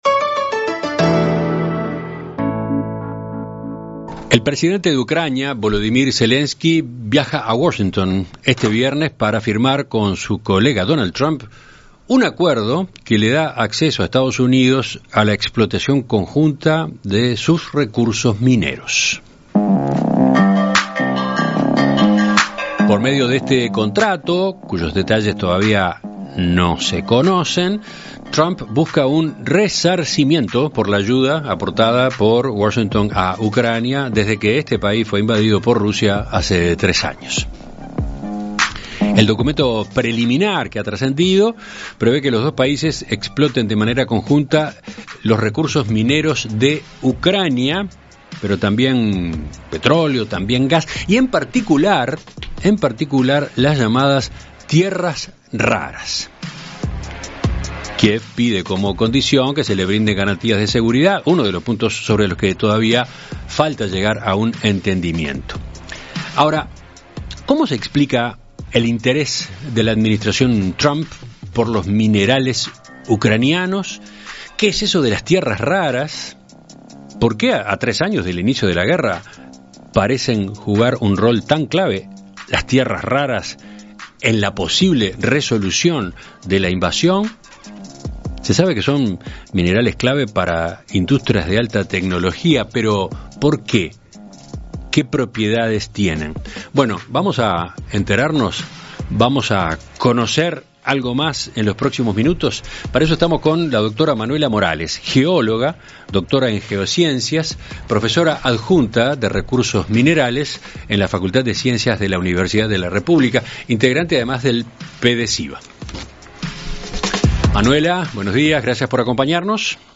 En Perspectiva Zona 1 – Entrevista Central